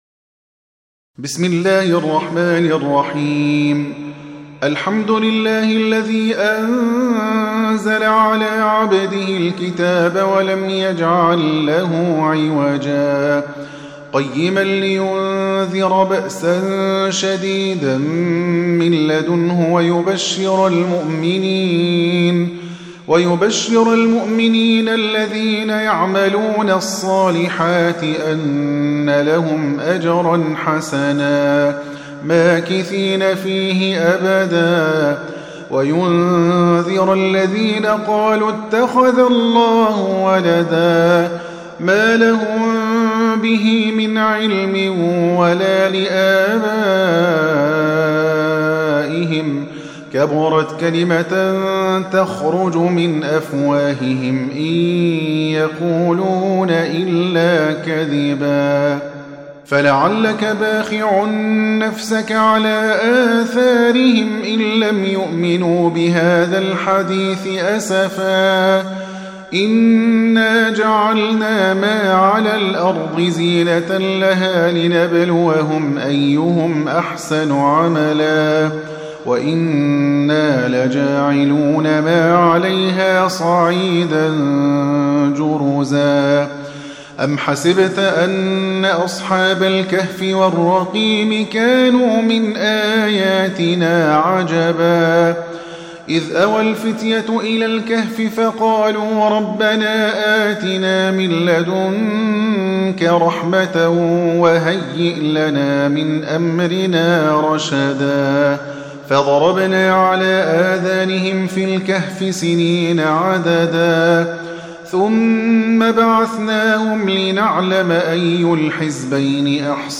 Surah Sequence تتابع السورة Download Surah حمّل السورة Reciting Murattalah Audio for 18. Surah Al-Kahf سورة الكهف N.B *Surah Includes Al-Basmalah Reciters Sequents تتابع التلاوات Reciters Repeats تكرار التلاوات